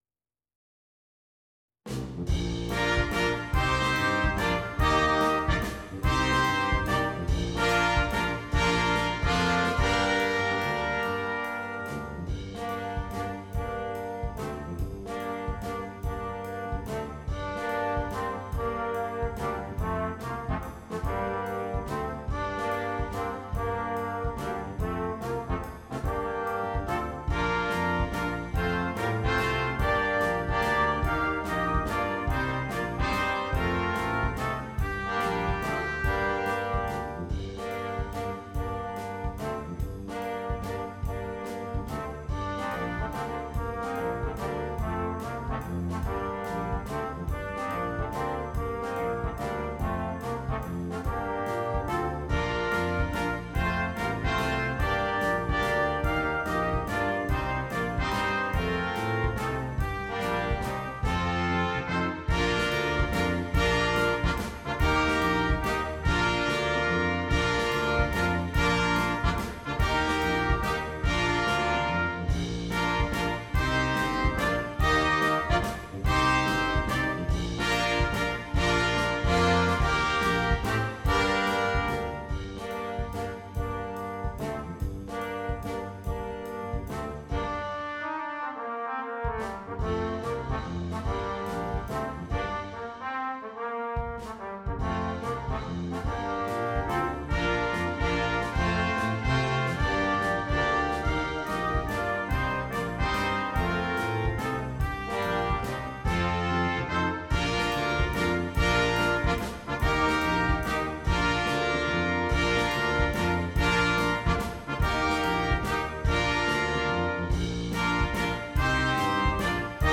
Brass Quintet (optional Drum Set)